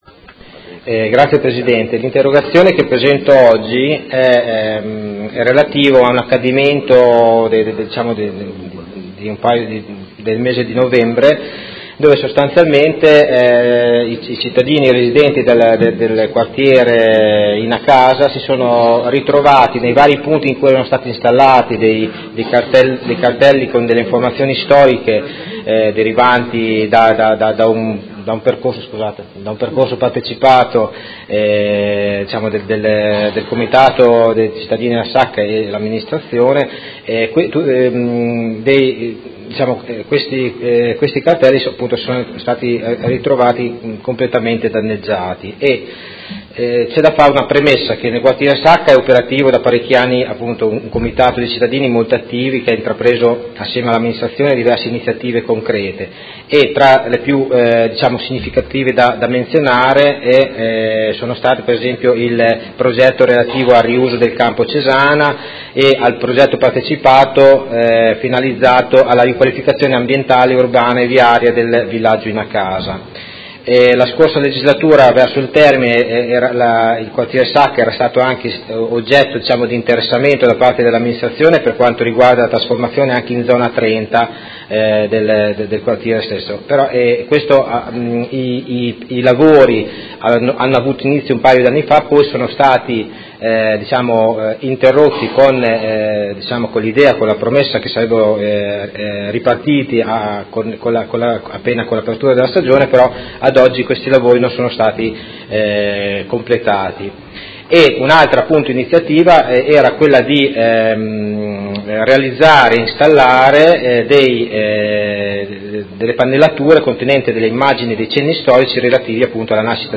Seduta dell'11/01/2018 Interrogazione del Consigliere Stella (Art.1-MDP/Per Me Modena) avente per oggetto: Preoccupazione per atti vandalici e degrado al Villaggio INA casa del quartiere Sacca e conclusione del progetto di trasformazione in zona 30